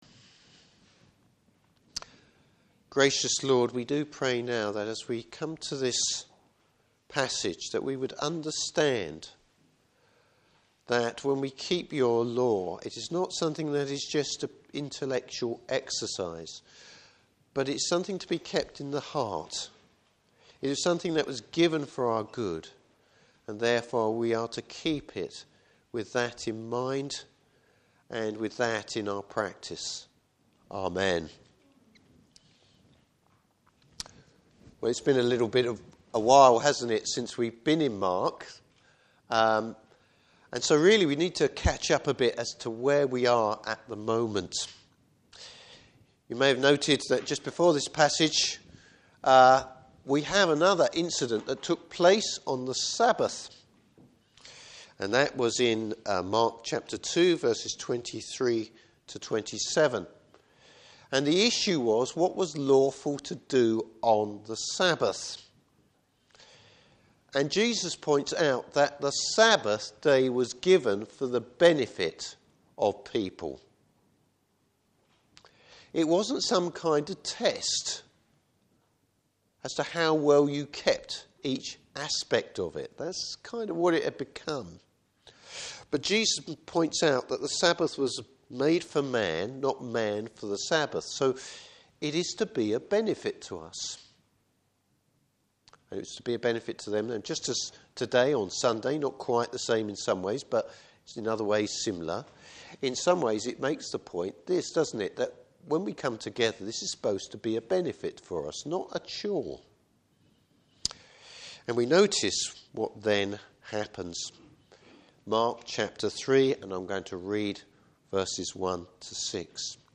Service Type: Morning Service The spirit of rest in the Lord.